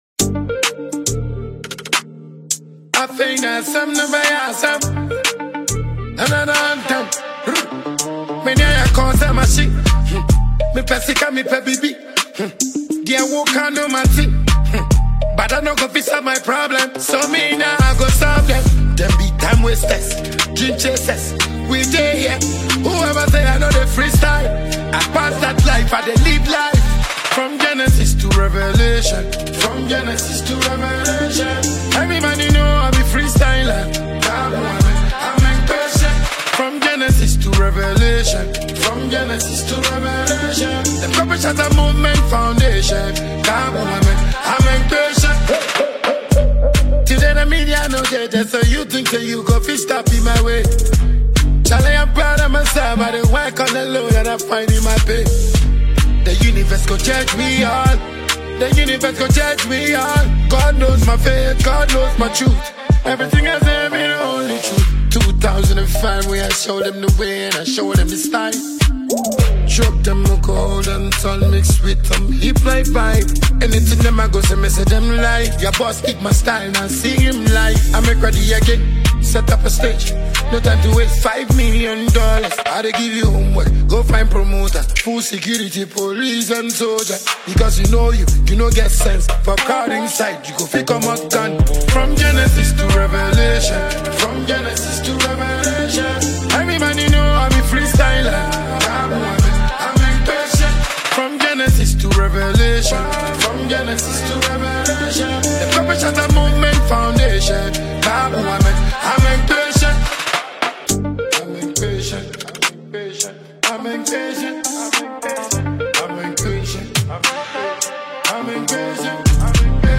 • Genre: Dancehall / Afrobeat / Inspirational